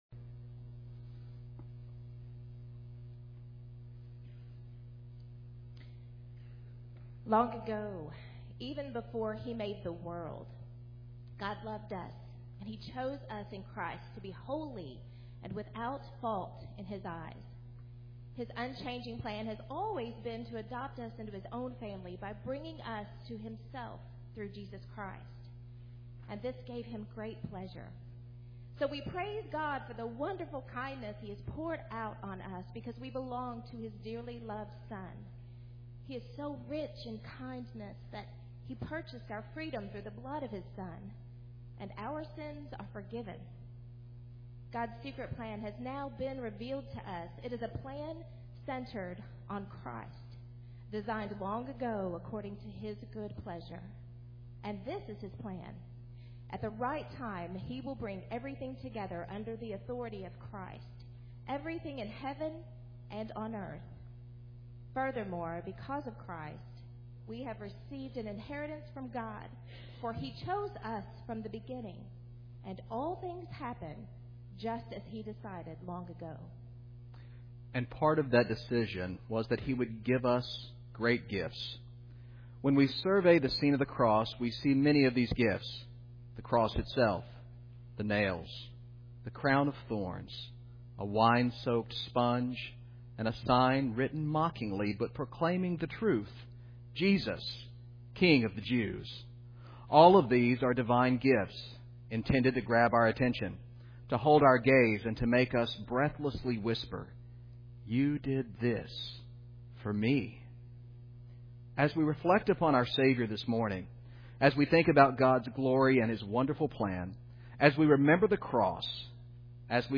He Chose the Nails A Reflection on the Cross of Christ Including the Ordinance of the Lord’s Supper HFBC Sunday Morning, November 2, 2014 Long ago, even before He made the world, God loved us and chose us in Christ to be holy and without fault in his eyes.